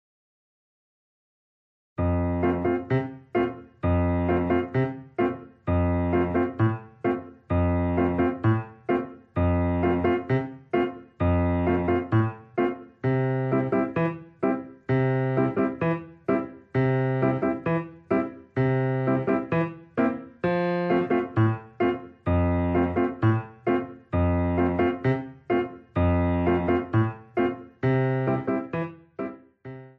Besetzung: Violine